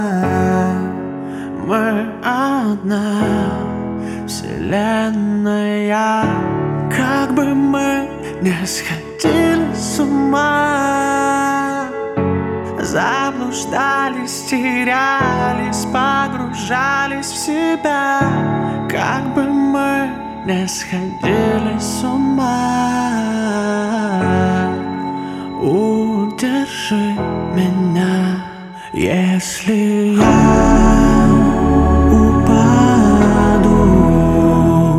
Жанр: Русская поп-музыка / Поп / Русский рок / Русские
# Pop